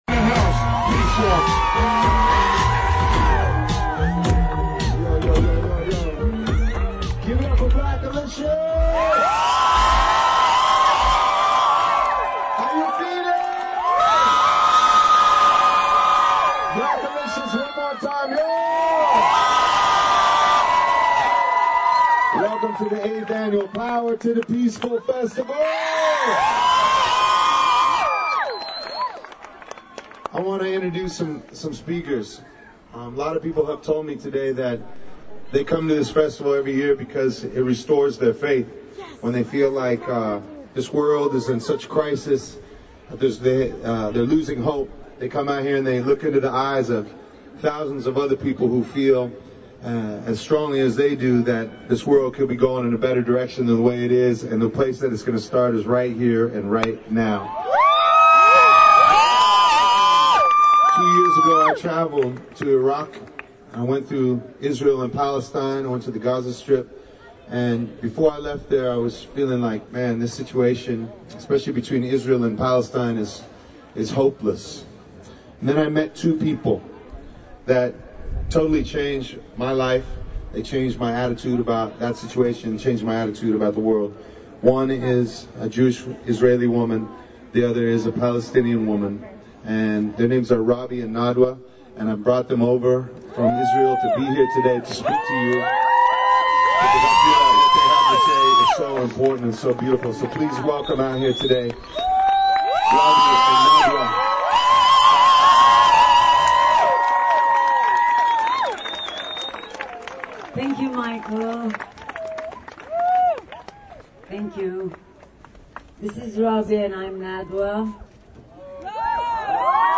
Michael Franti discusses his recent trip to Baghdad. He introduced two women peace activists, one from Israel and the other from Palestine.
The theme of the entire day had to do with being peace, a very powerful and moving event in Golden Gate Park.